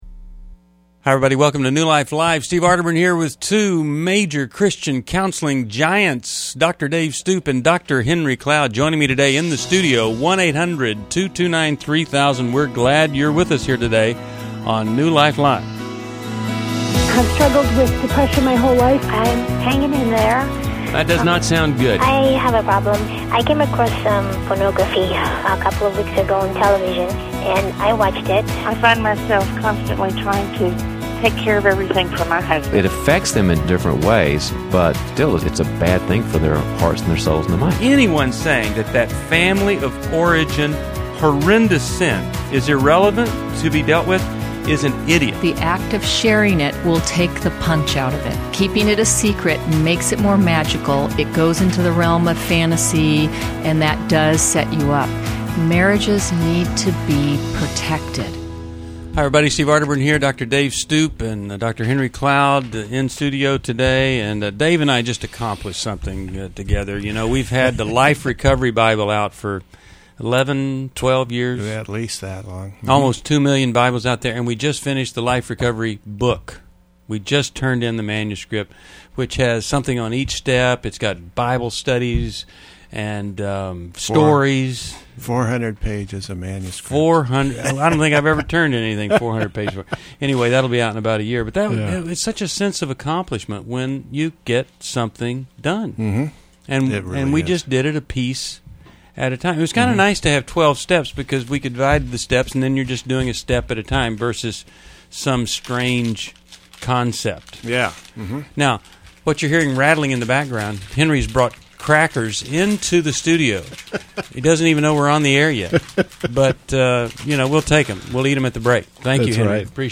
Explore family dynamics and emotional healing in New Life Live: November 22, 2011, as hosts tackle caller concerns about parenting, relationships, and childhood trauma.